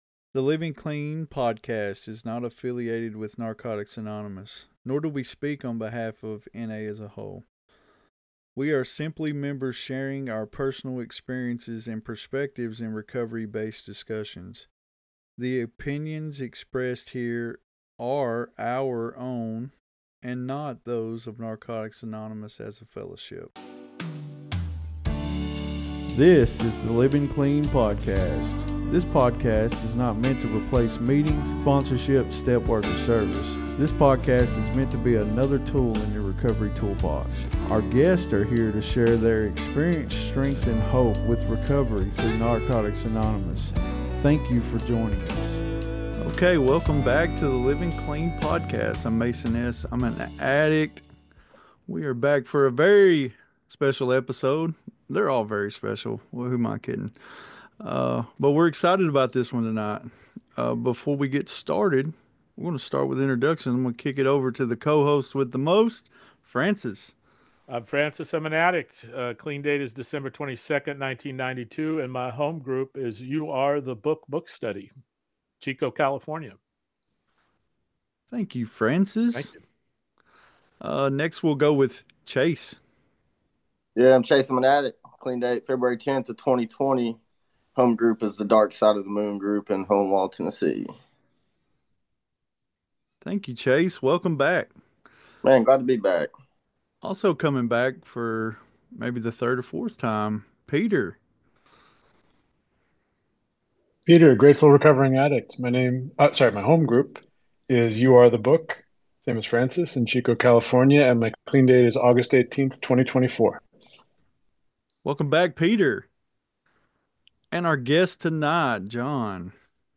In this special episode, we sit down with some of the founding members of the The International Online Marathon Meeting to hear the incredible story of how it all began. What started as a handful of addicts gathering on Zoom to share their recovery journeys has grown into a global phenomenon—a 24-hour-a-day meeting that’s been running nonstop for nearly five years.